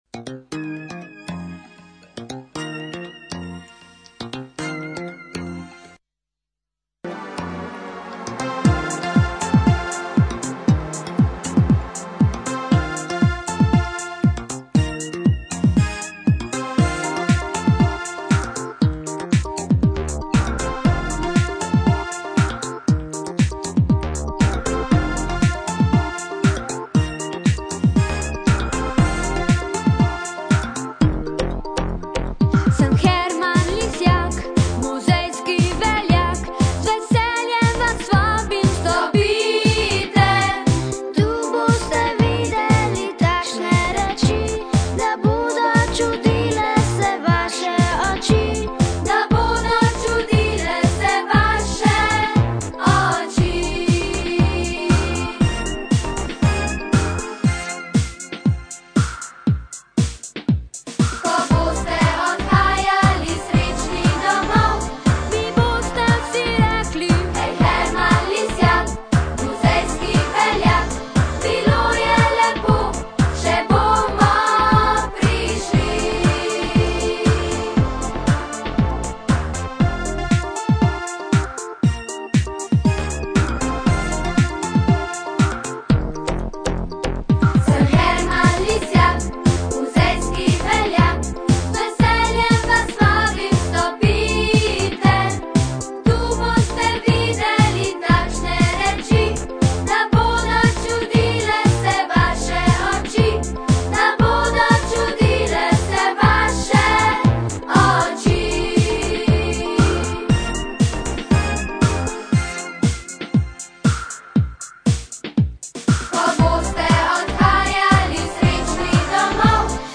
HERMAN-HERMAN_himna_vokalna.mp3